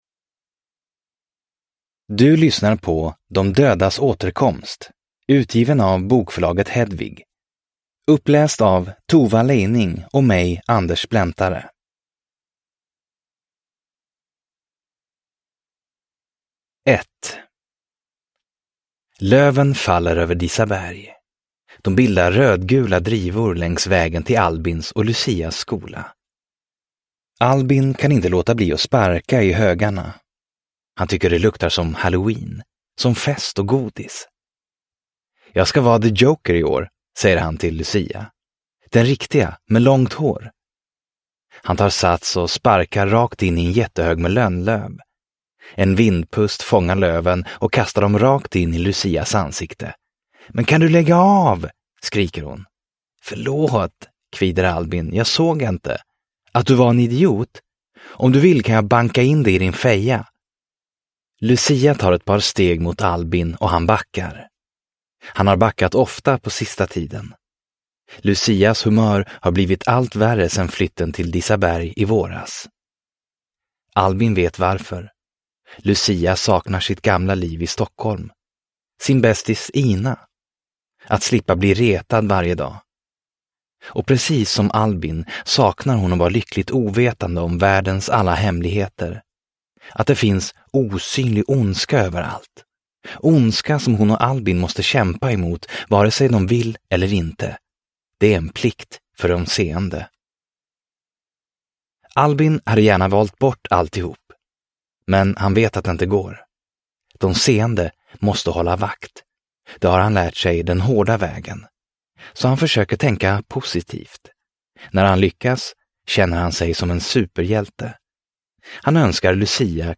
De dödas återkomst – Ljudbok